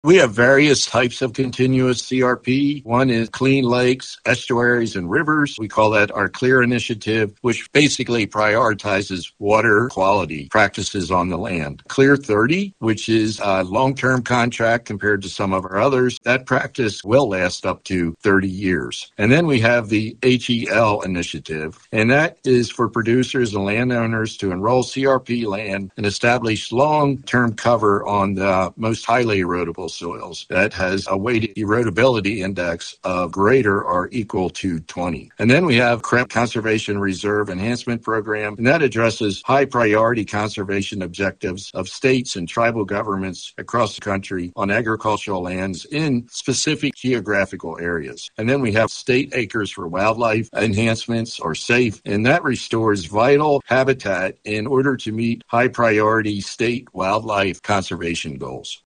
Farm Service Agency Administrator Bill Beam discusses the various options available for producers and landowners to enroll acreage under the Continuous Conservation Reserve Program…